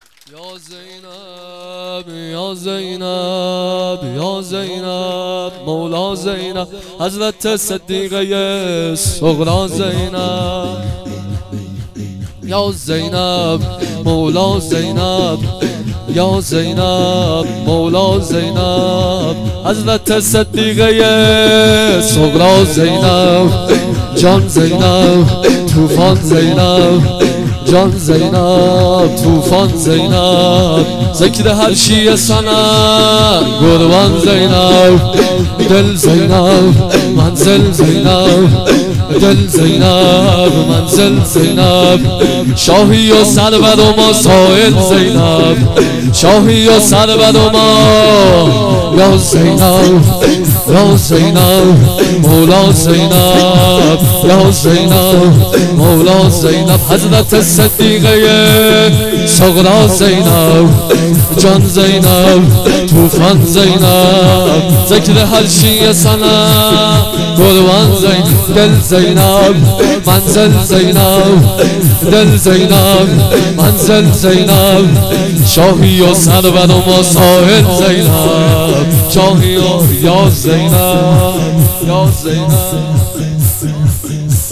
سبک مولودی